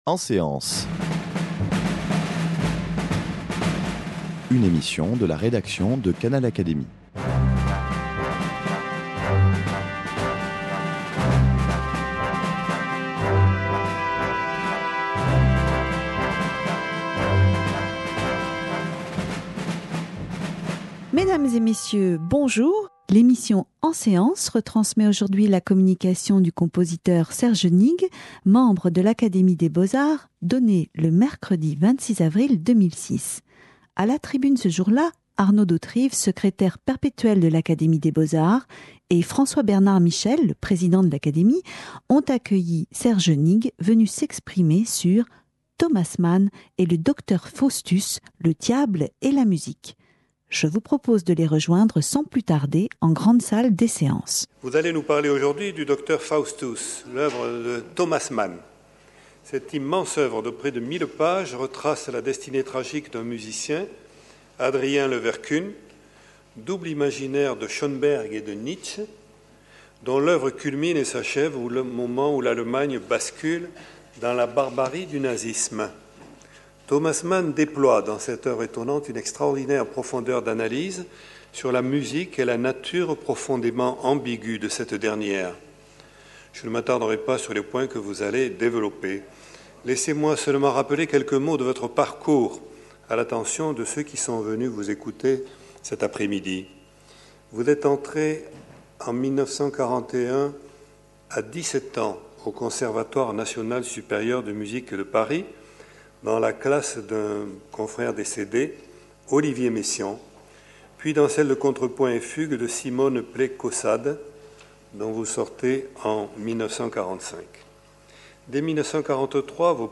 Le compositeur Serge Nigg s’exprime sur le lien entre la littérature et la musique à travers l’ouvrage de Thomas Mann, Docteur Faustus, publié en 1947.